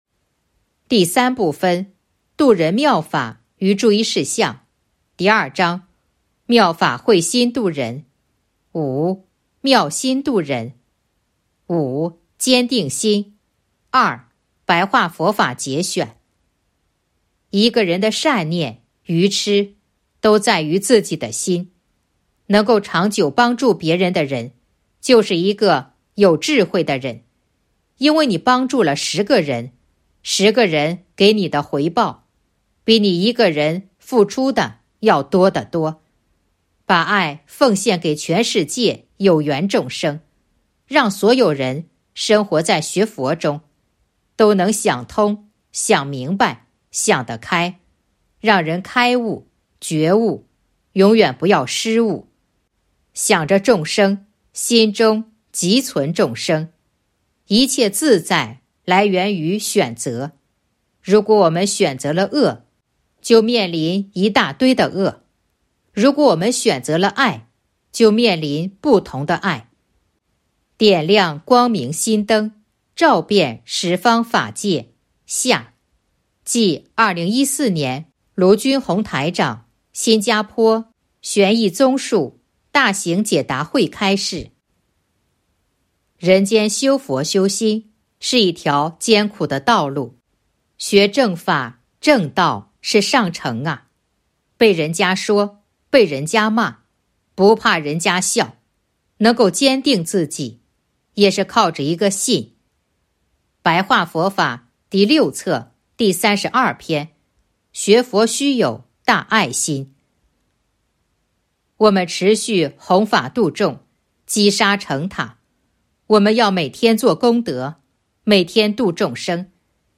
白话佛法节选《弘法度人手册》【有声书】